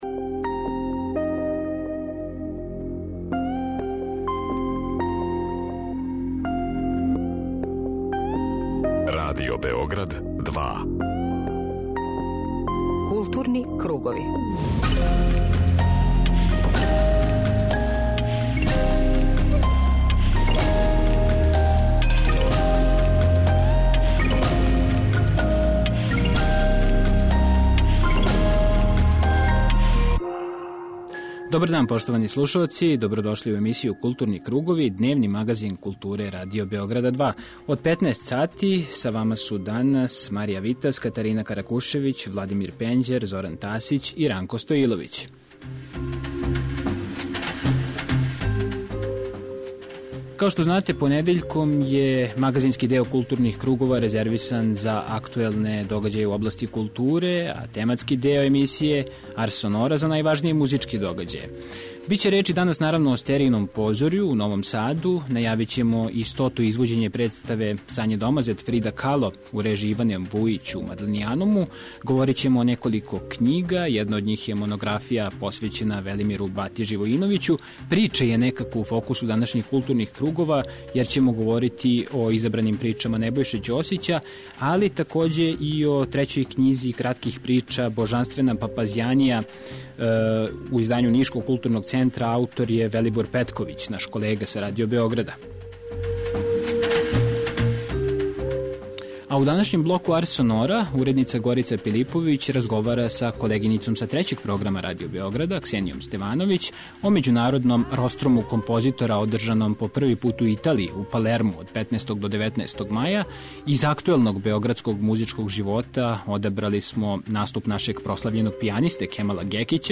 преузми : 40.24 MB Културни кругови Autor: Група аутора Централна културно-уметничка емисија Радио Београда 2.